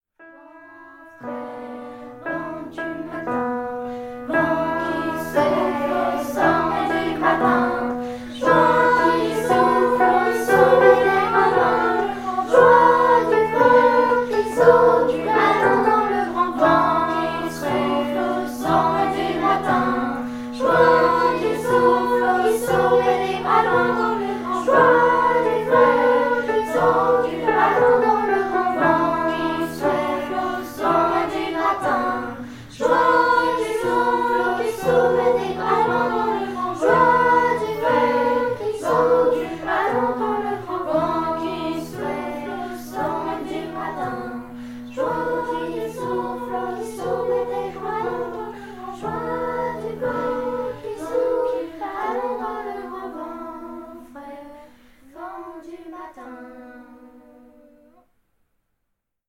LA CHORALE FAIT SA RENTREE
Quelques élèves de l'option chorale ont commencé les répétitions et ont déjà enregistré un petit chant en canon : Vent Frais.